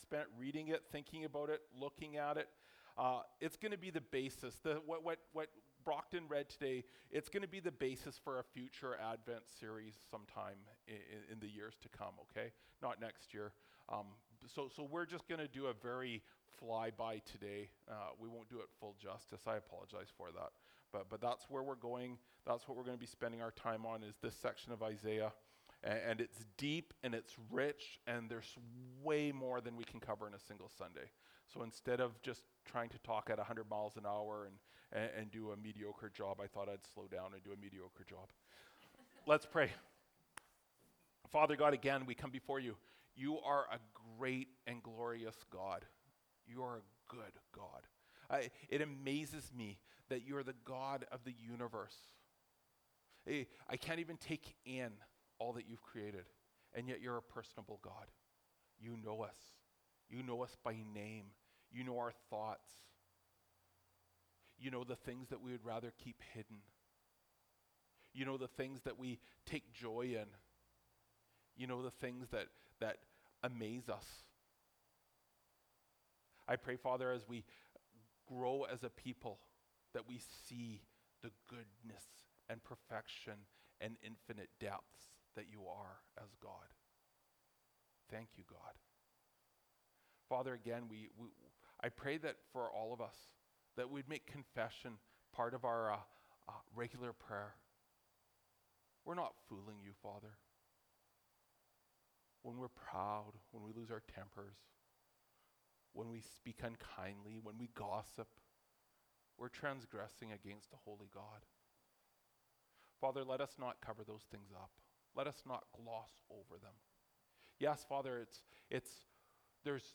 Dec 17, 2023 The Fourth Servant Song (Isaiah 52:13-53:12) MP3 SUBSCRIBE on iTunes(Podcast) Notes Discussion Sermons in this Series This sermon was recorded in Grace Church Salmon Arm (and also preached in Grace Church Enderby).